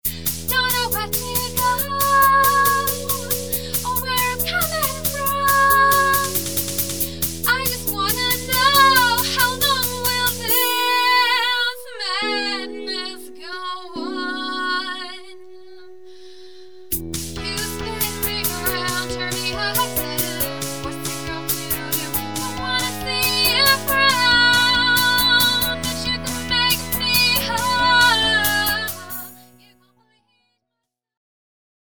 More like extreme teeny bopperish pop, but hey, they can play their own instruments!!
HERE ......actually sung XD XD XD (...ok, this is NOT pleasant xD Mind your ears don't explode or anything.)